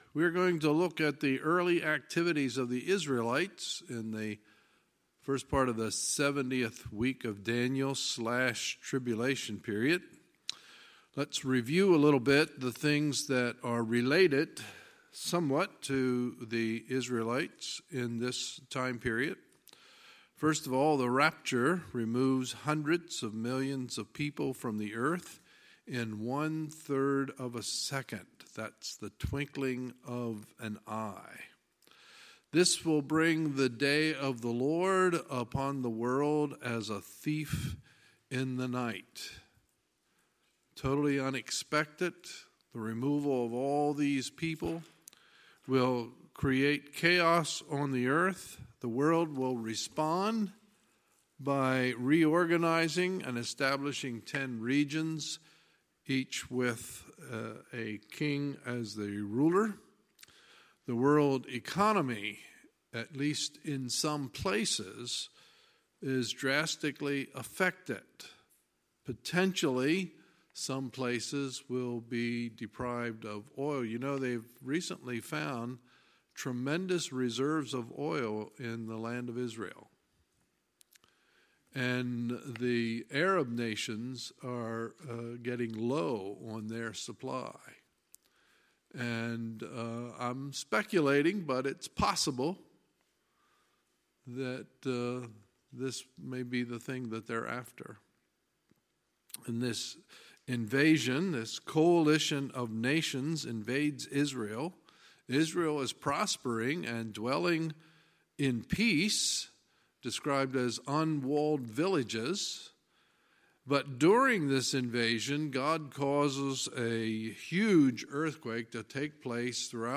Sunday, December 16, 2018 – Sunday Evening Service
Sermons